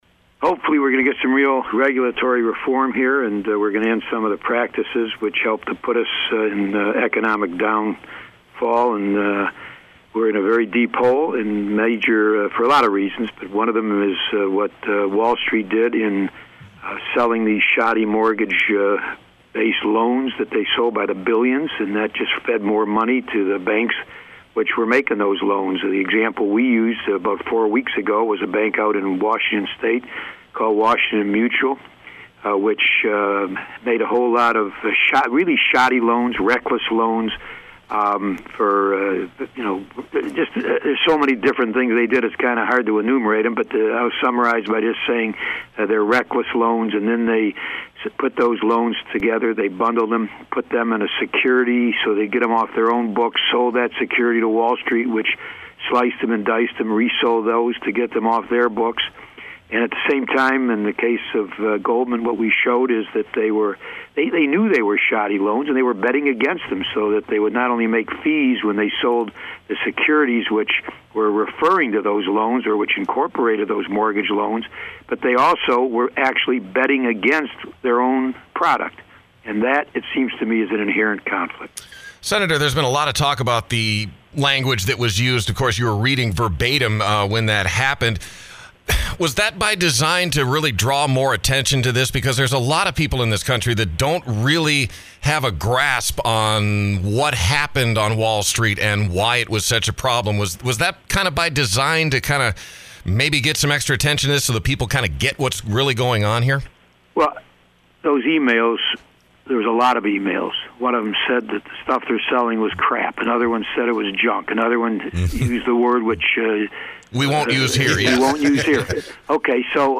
Michigan’s Senior U.S. Senator, Carl Levin checked in with us today for a chat on the Financial Regulatory Reform Bill currently under debate in Congress. We also talked about his eyebrow-raising questioning in the recent subcommittee hearing in the Senate looking into improper investing by the Goldman Sachs firm.